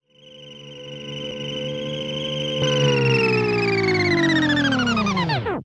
droidenters.wav